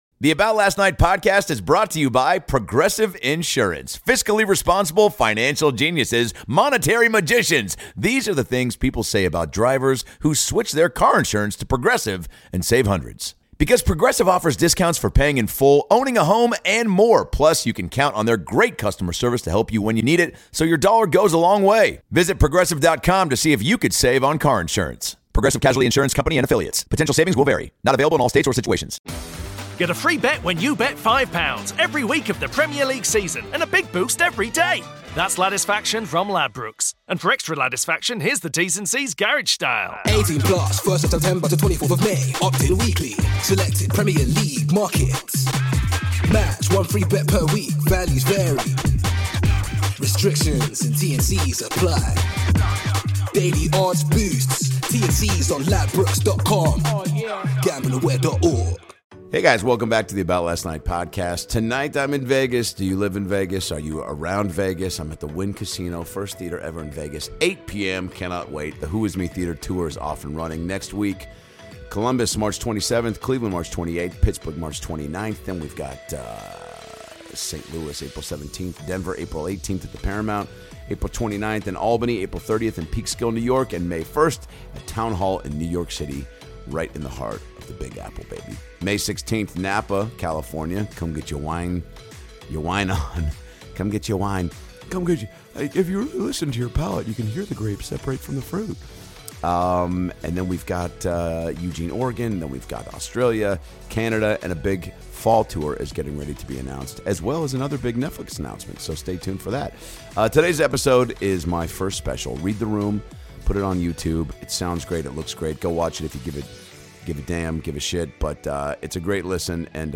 Recorded at the prestigious PUNCHLINE COMEDY CLUB in San Francisco, CA in 2019, Adam Ray drops an hour of hilarious jokes about his nieces, dreams of being in the NBA, pot brownie freak outs, and loving drunk couple fights.